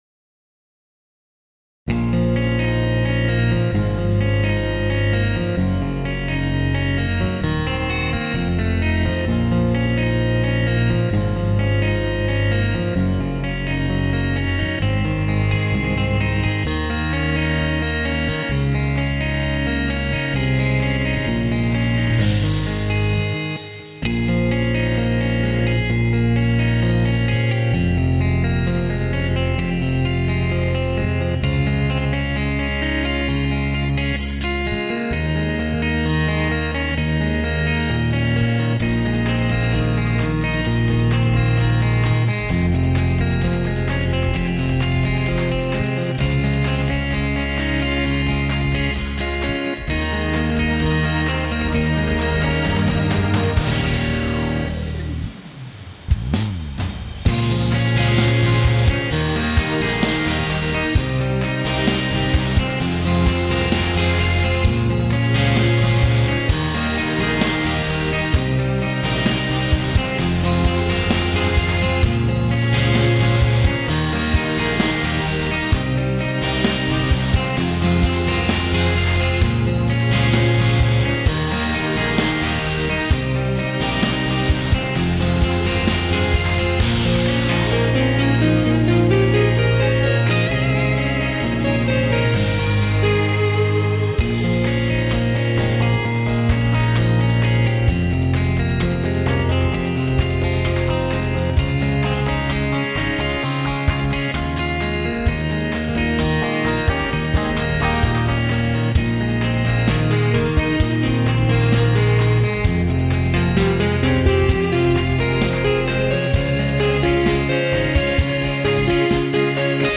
ТИП: Пісня
СТИЛЬОВІ ЖАНРИ: Ліричний
З голосом заходить краще) wink
Гітара 12 12 12